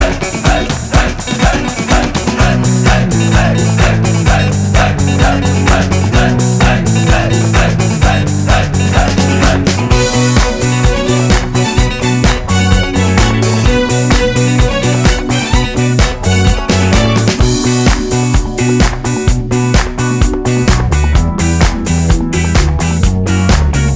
Duet Disco 4:38 Buy £1.50